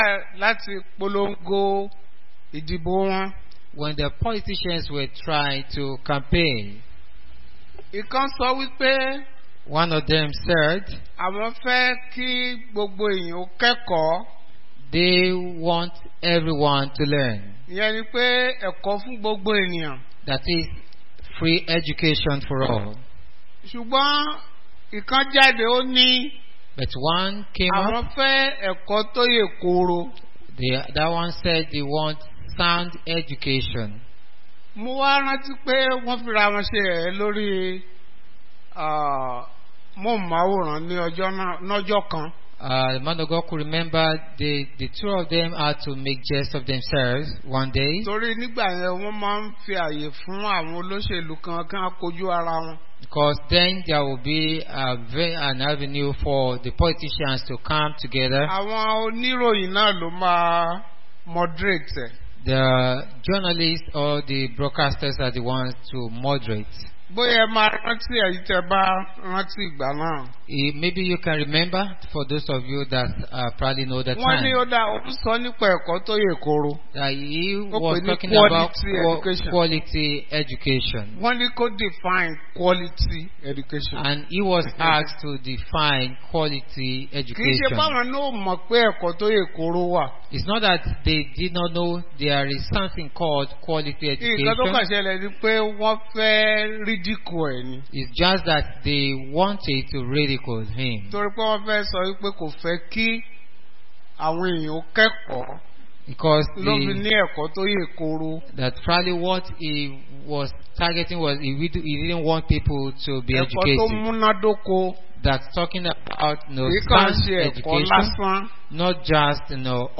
Bible Class Passage: Ephesians 6:18